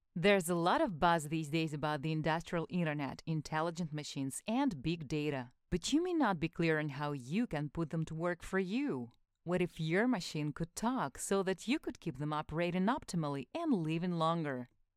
Жен, Рекламный ролик/Средний
Конденсаторный микрофон Behringer B-1, звуковая карта Audient Evo 4